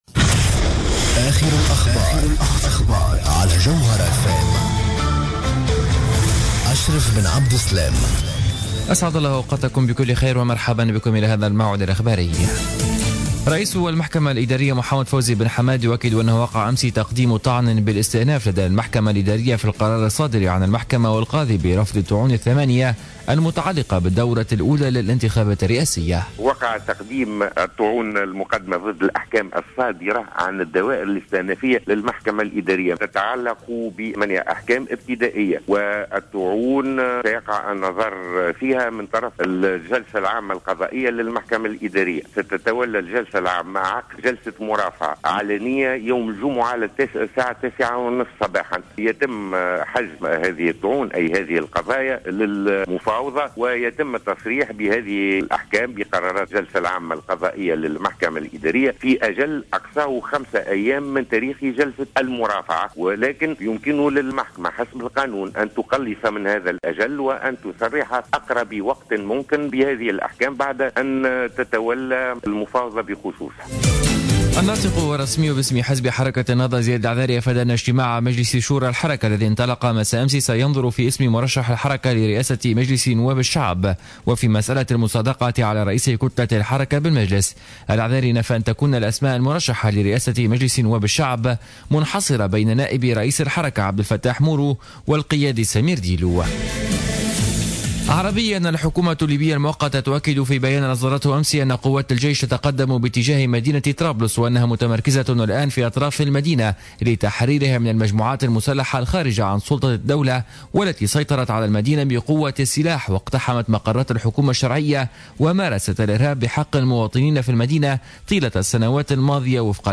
نشرة أخبار منتصف الليل ليوم 04-12-14